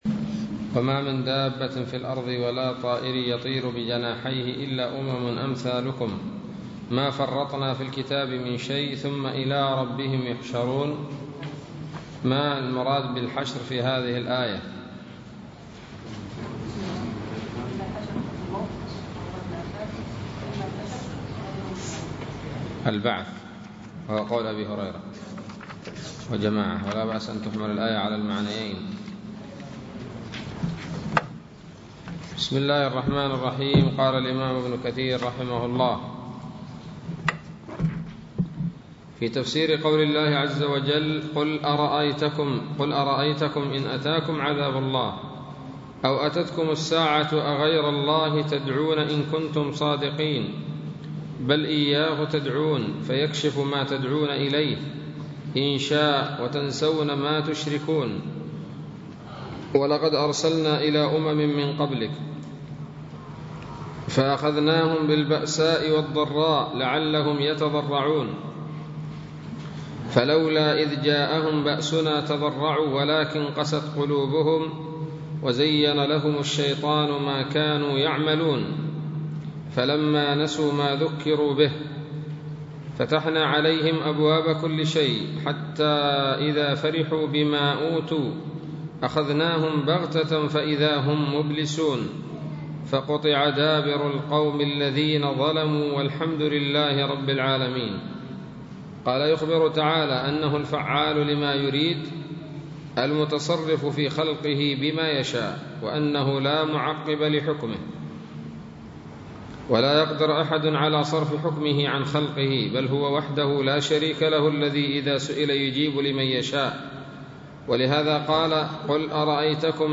الدرس التاسع من سورة الأنعام من تفسير ابن كثير رحمه الله تعالى